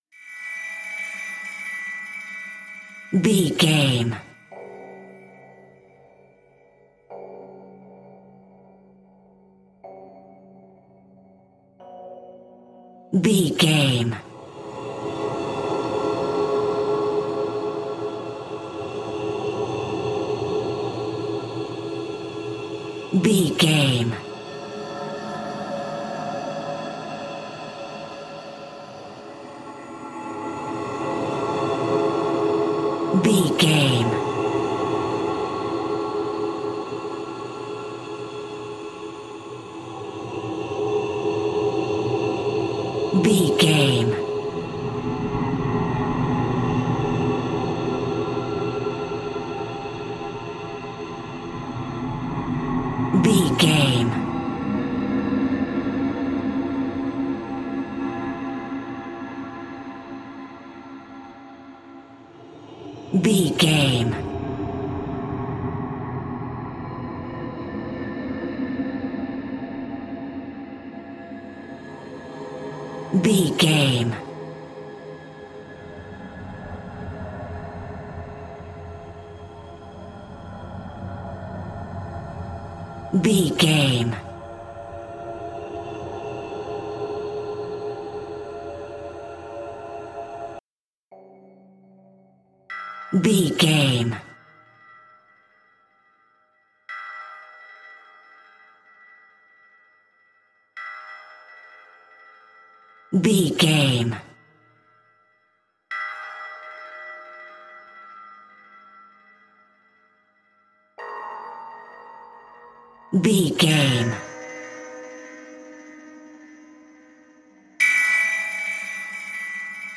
Aeolian/Minor
ominous
dark
eerie
horror music
Horror Pads
Horror Synths